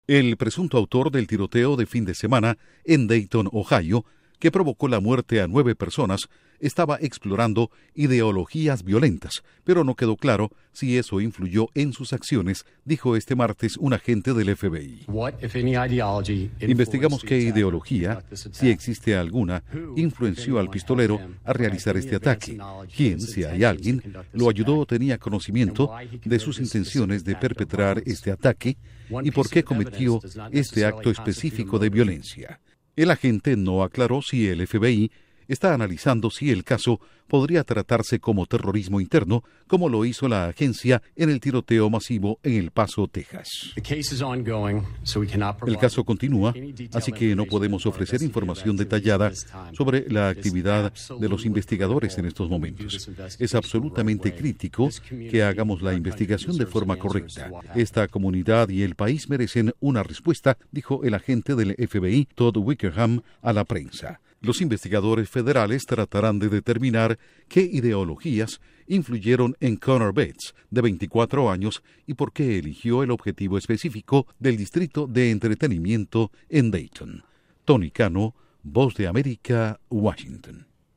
FBI: sospechoso de tiroteo en Ohio exploró “ideologías violentas”. Informa desde la Voz de América en Washington